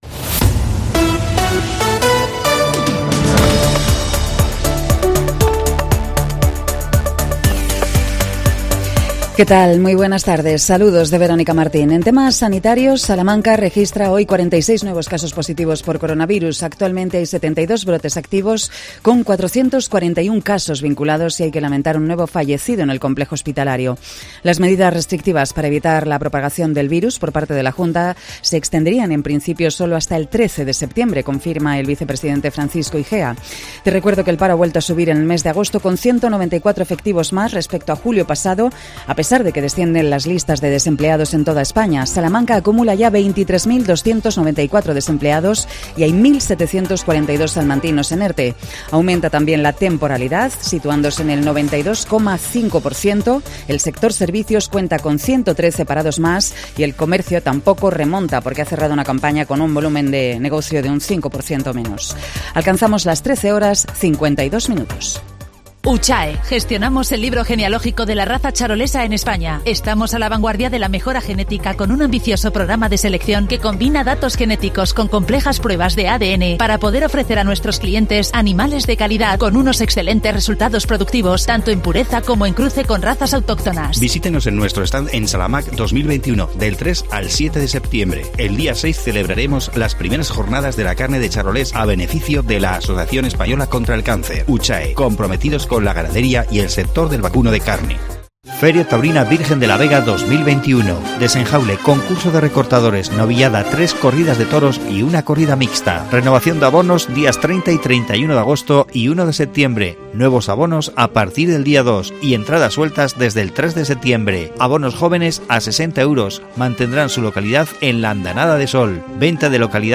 AUDIO: Javier Iglesias, presidente de la Diputación Provincial informa sobre los detalles de SALAMAQ 2021